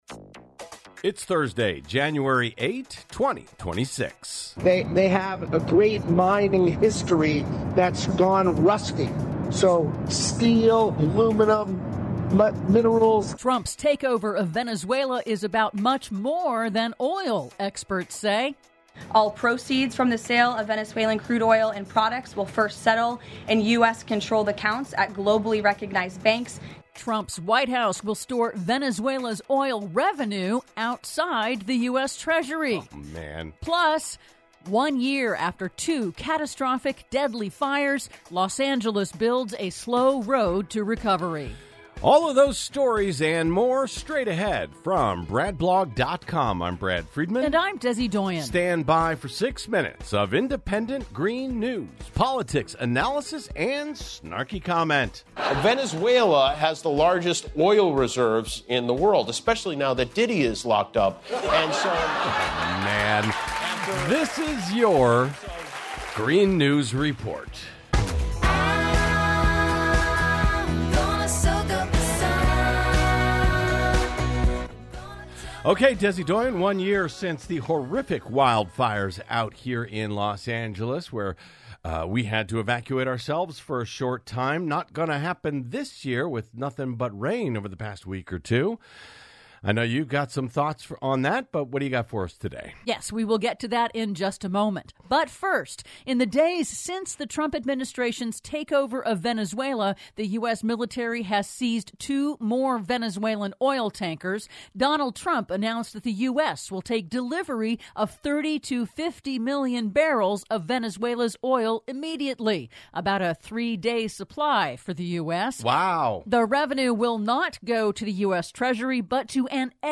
IN TODAY'S RADIO REPORT: Trump's takeover of Venezuela is about much more than oil, experts say; Trump White House will store Venezuela's oil revenue outside the U.S. Treasury; Extreme snow in Alaska sinks boats, collapses roofs as FEMA plans to lay off disaster recovery staff; PLUS: One year after two catastrophic, deadly fires, Los Angeles builds a slow road to recovery... All that and more in today's Green News Report!